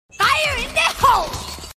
Fake Raze Ult Enemy sound effects free download
Fake Raze Ult Enemy - Meme Effect Sound